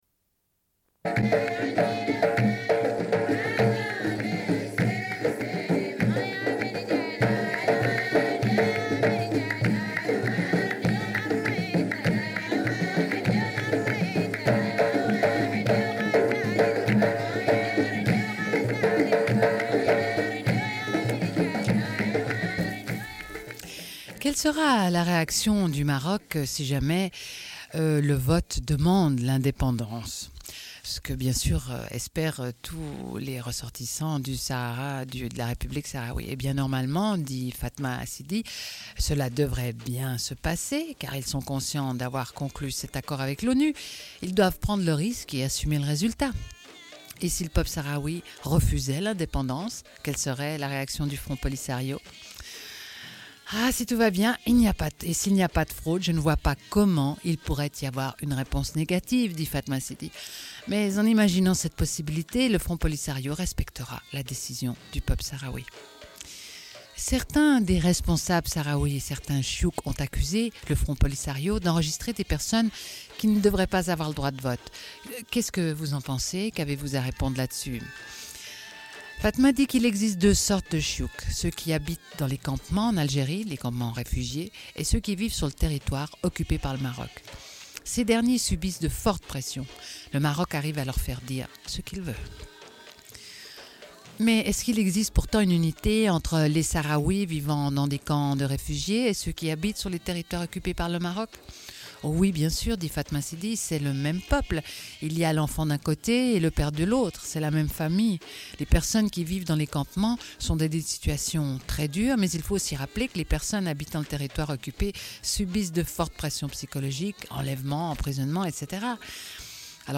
Une cassette audio, face B
Radio